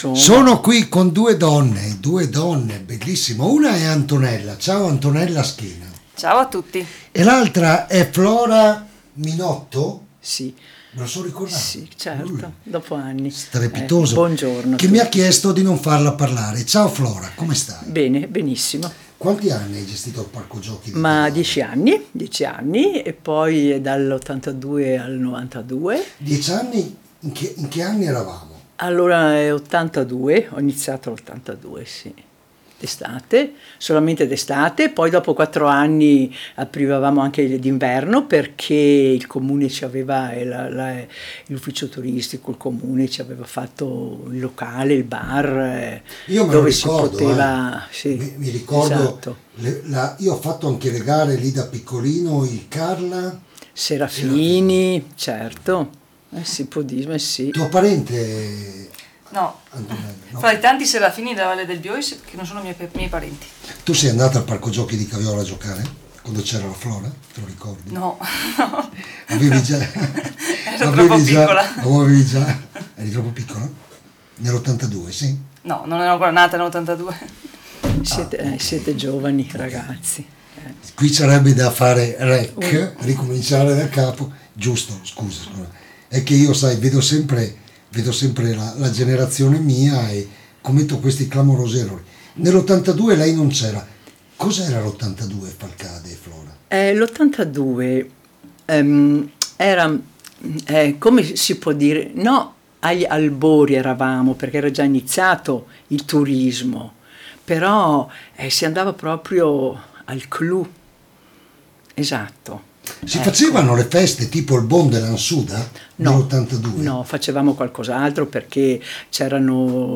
GIORNALE RADIOPIU